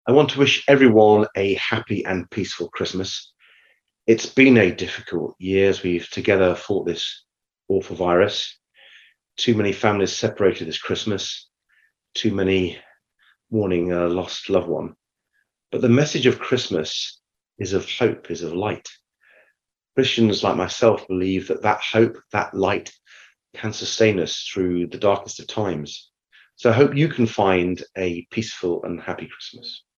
Kingston MP Sir Ed Davey's Christmas Message